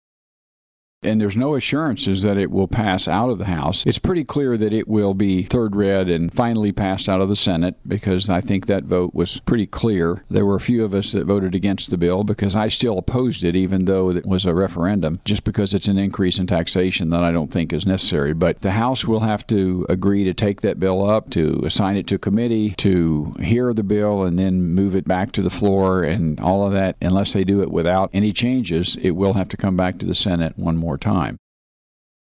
The following cuts are taken from the above interview with Sen. Emery, for the week of March 28, 2016.